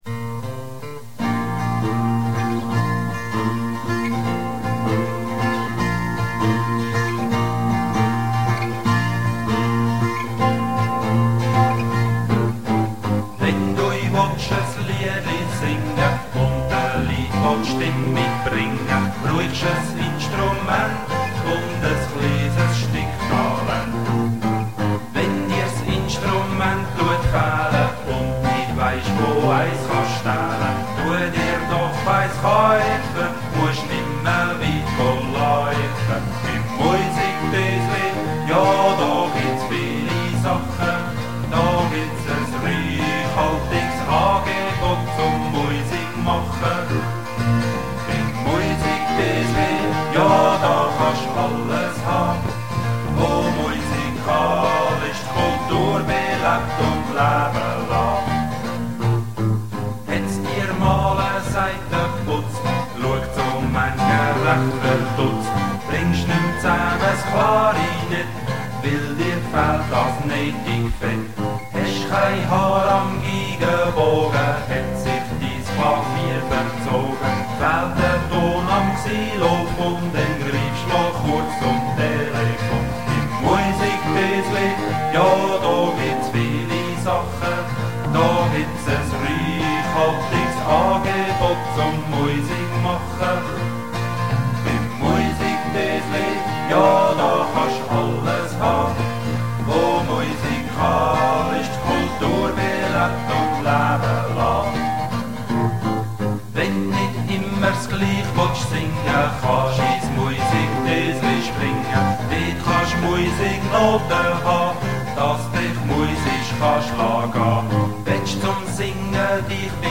(komponiert zur Geschäfts-Eröffnung 1988, Originalaufnahme)